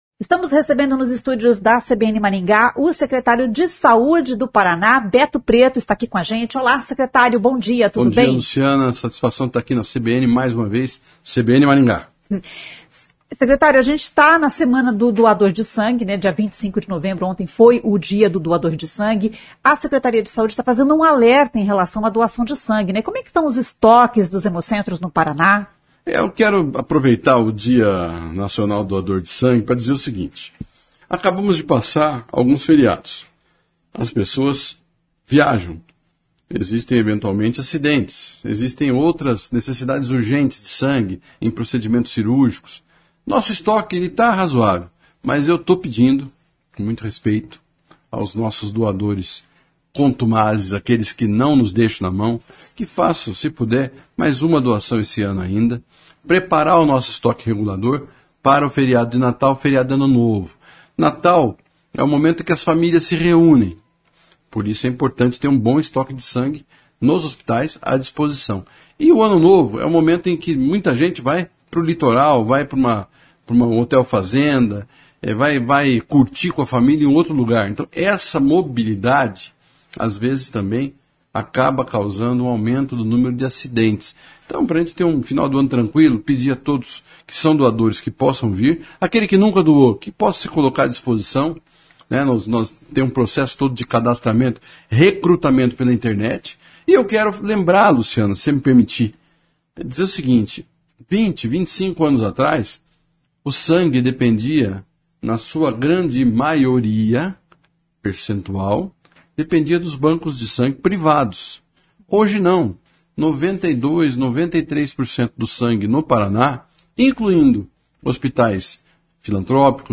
Secretário de Saúde do Paraná fala sobre a doação de sangue